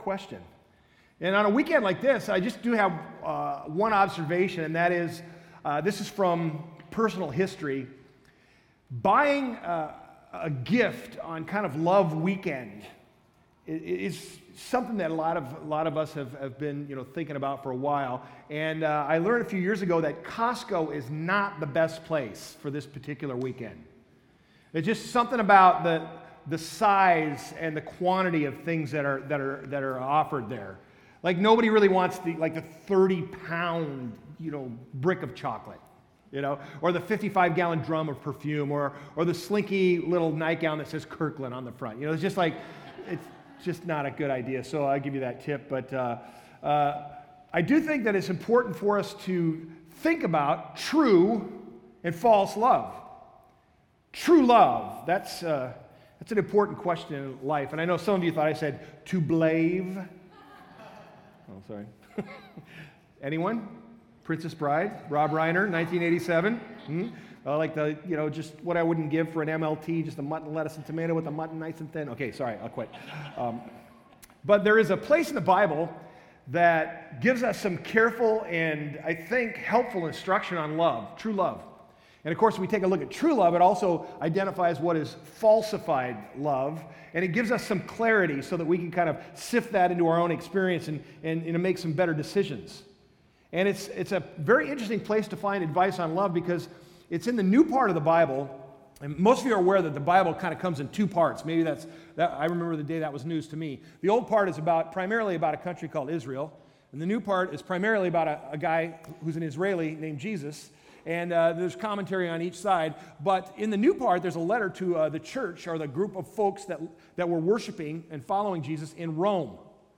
Date: 02/08/2015 Message Begins at 20:42